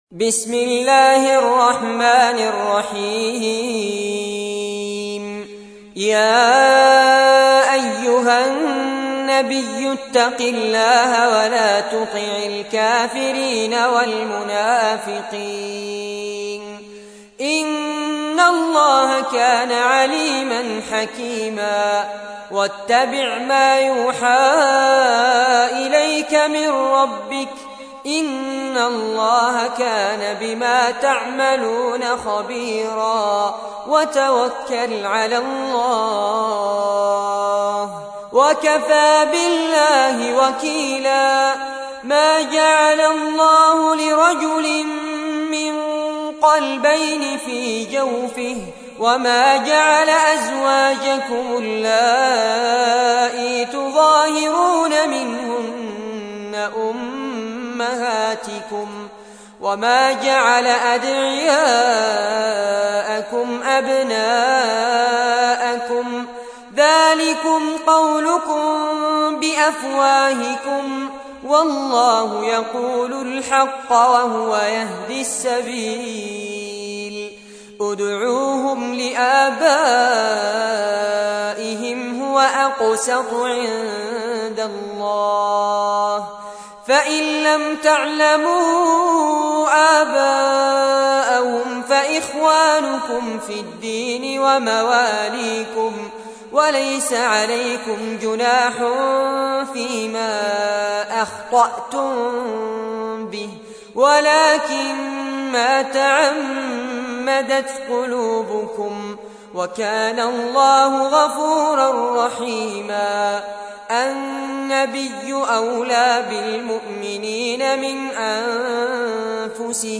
تحميل : 33. سورة الأحزاب / القارئ فارس عباد / القرآن الكريم / موقع يا حسين